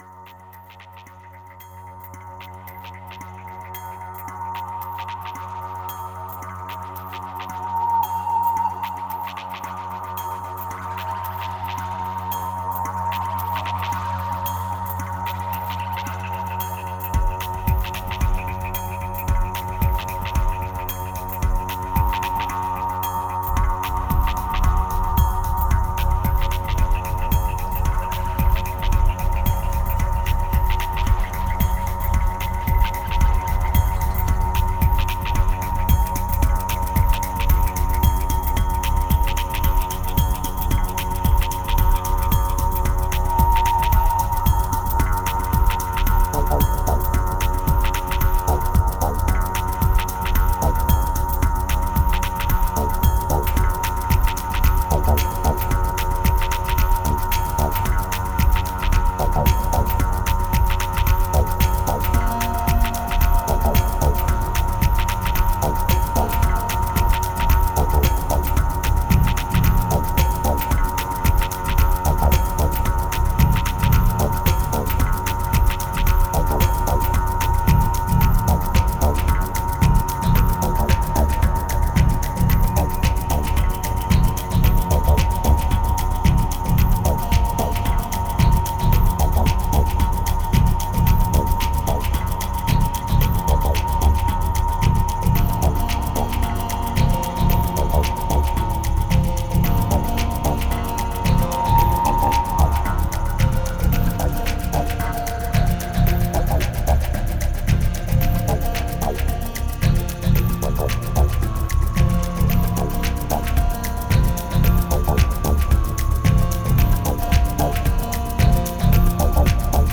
1812📈 - 20%🤔 - 112BPM🔊 - 2013-04-20📅 - -19🌟